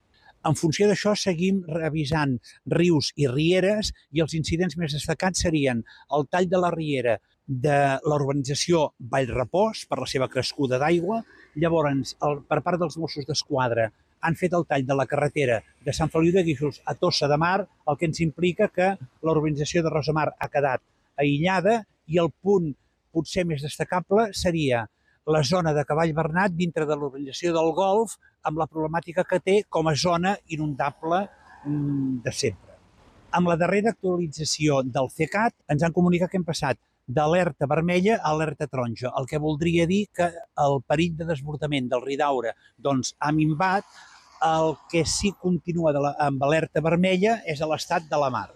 L’alcalde de Santa Cristina d’Aro, Josep Xifre, també explica que la incidència més rellevant que es va produir aquest dimarts amb la llevantada va ser el tall de la carretera de Sant Feliu de Guíxols a Tossa de Mar.